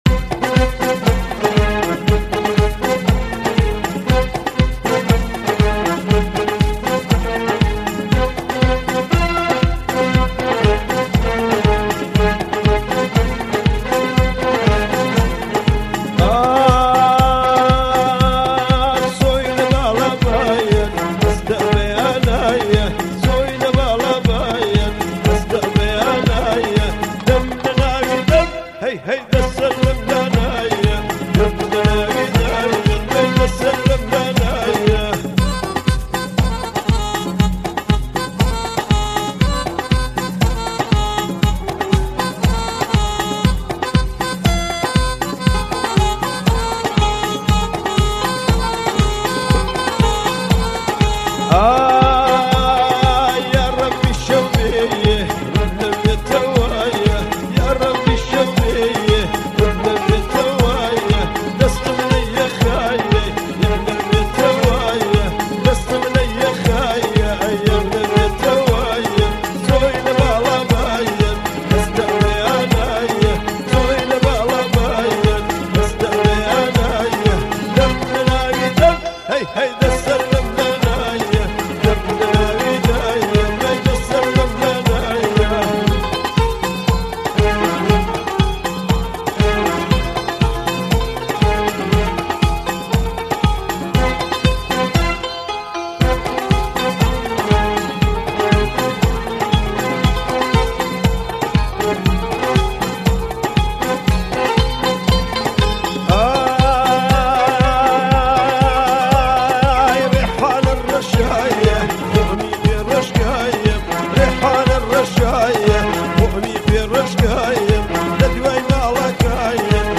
آهنگ کردی فولکلور